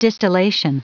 Prononciation du mot distillation en anglais (fichier audio)
Prononciation du mot : distillation